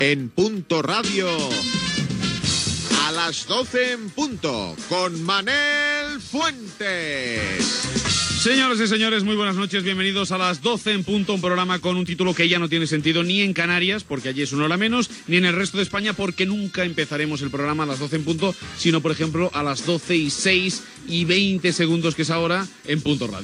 Inici del primer programa: careta i presentació
Entreteniment